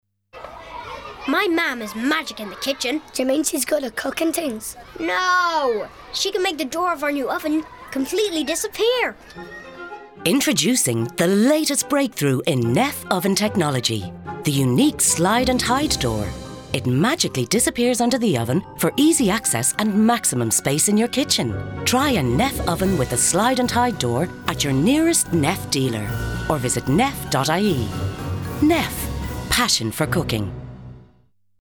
A 30 second Radio Ad promoting the unique ‘Hide and Slide’ oven door – an exclusive and sought-after feature only available on NEFF ovens.